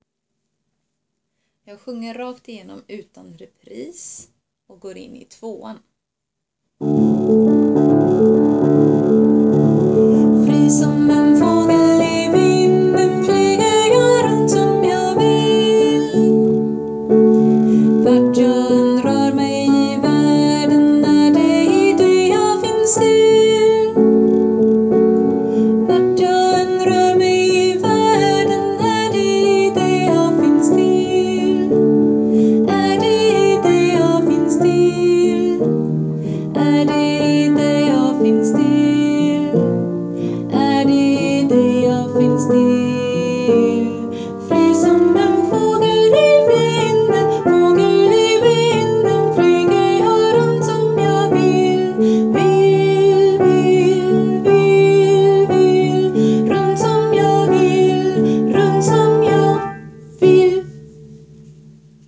Fri som en fågel alt
fri som alt.wav